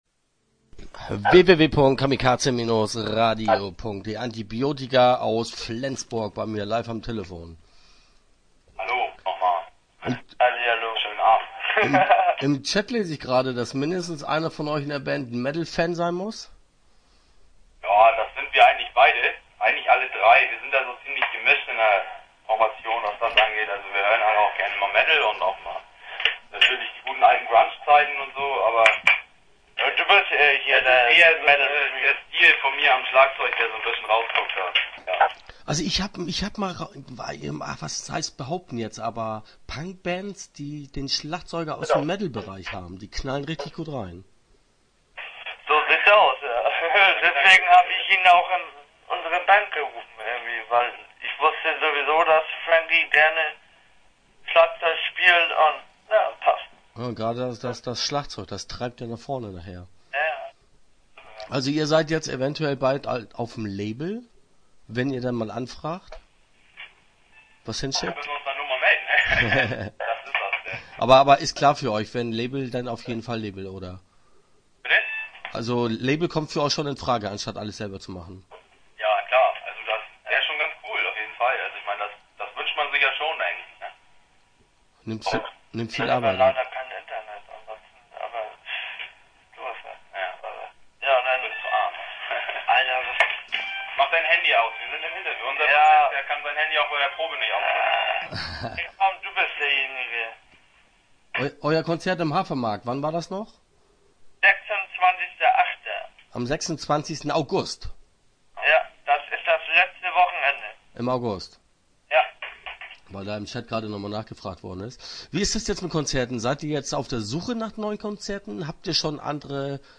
Start » Interviews » Antibiotika